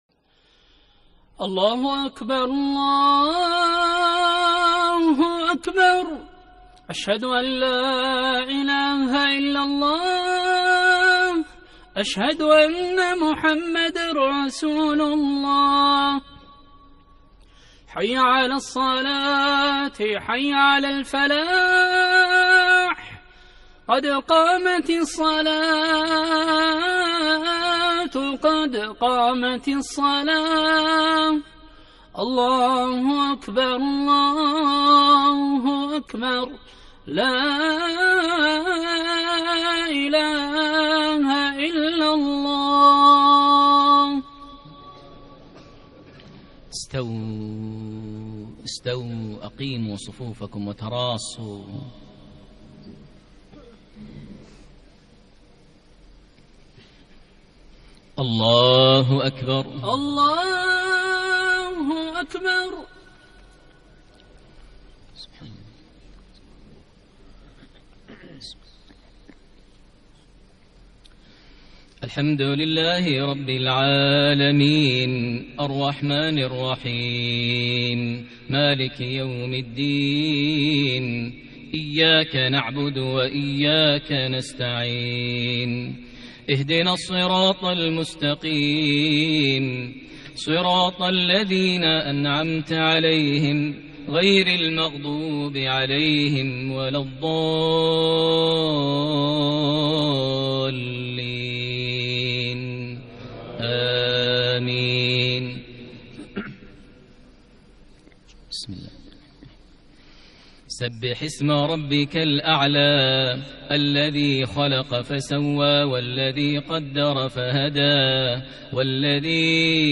صلاة الجمعة 3-6-1440هـ سورتي الأعلى و الغاشية > 1440 هـ > الفروض - تلاوات ماهر المعيقلي